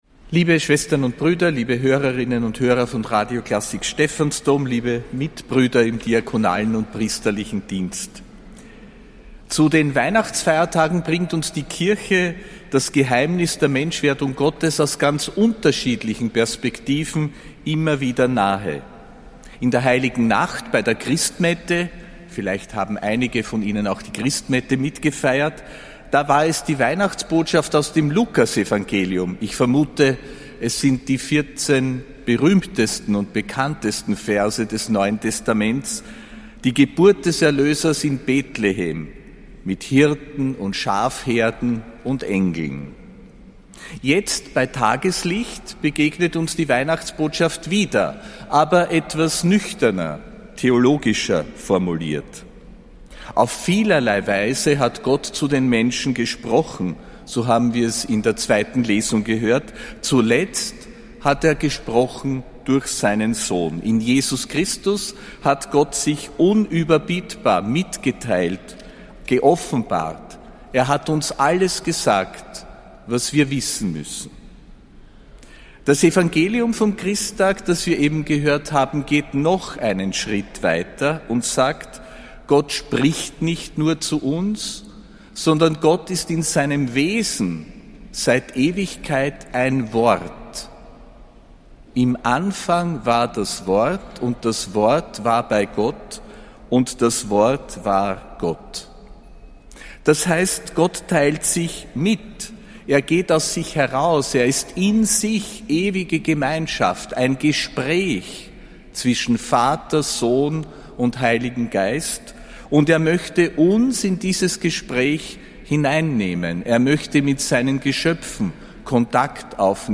Predigt von Josef Grünwidl zum Christtag (25. Dezember 2025)